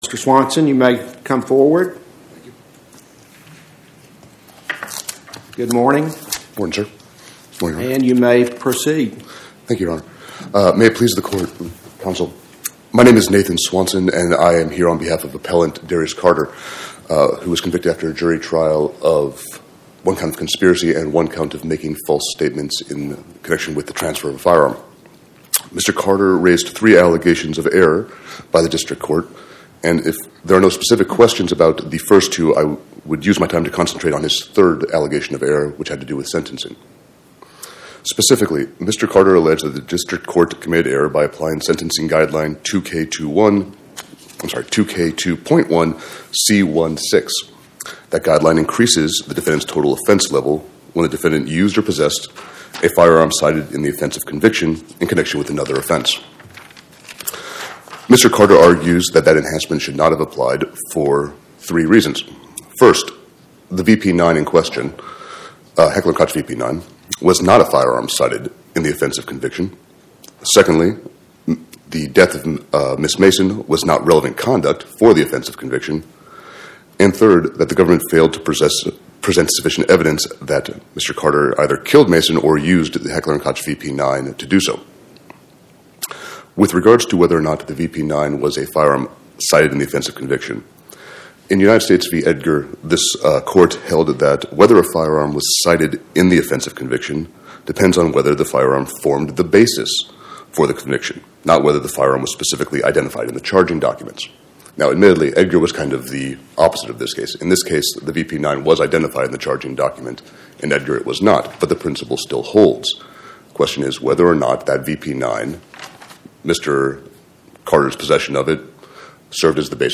Oral argument argued before the Eighth Circuit U.S. Court of Appeals on or about 01/13/2026